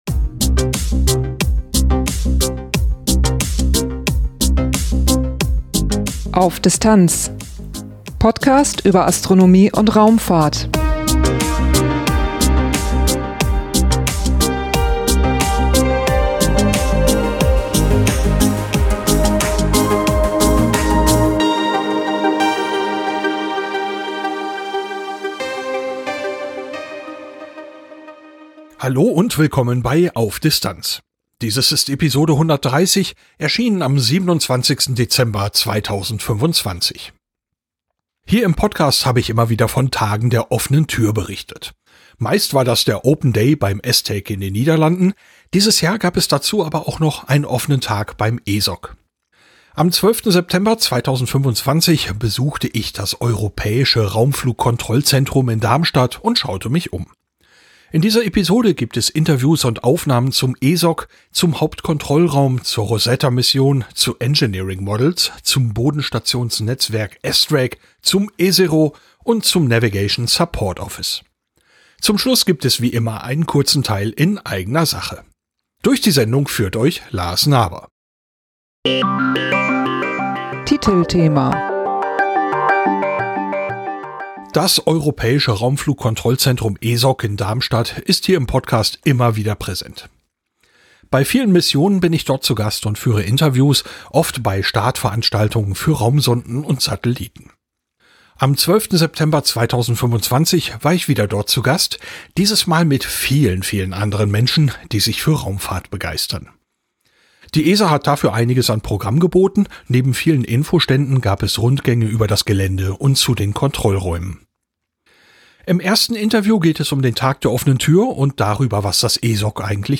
Am 12. September 2025 besuchte ich das europäische Raumflugkontrollzentrum in Darmstadt und schaute mich um. In dieser Episode gibt es Interviews und Aufnahmen zum ESOC, zum Hauptkontrollraum, zur Rosetta-Mission, zu Engineering models, zum Bodenstationsnetzwerk ESTRACK, zum ESERO und zum Navigation Support Office.